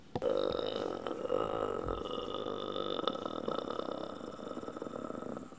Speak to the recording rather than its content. Les sons ont été découpés en morceaux exploitables. 2017-04-10 17:58:57 +02:00 962 KiB Raw History